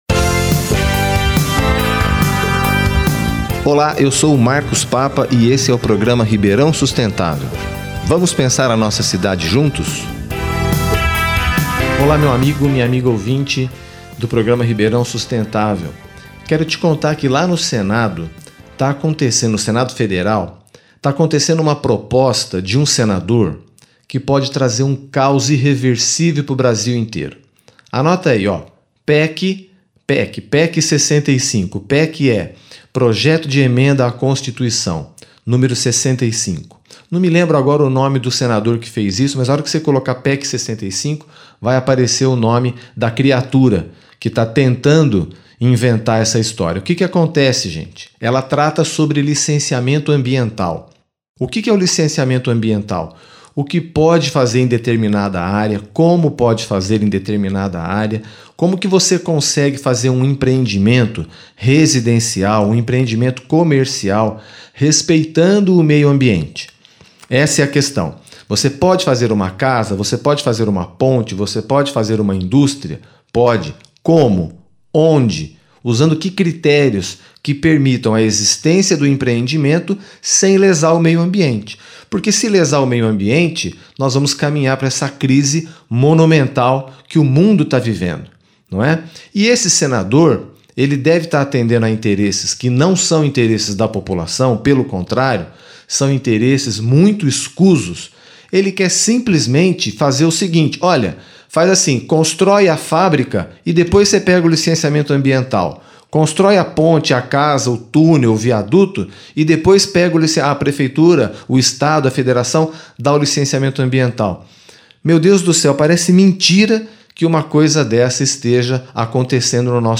No rádio, meu programa vai ao ar de Segunda a Sexta, dentro do Larga Brasa da 79 (590 AM):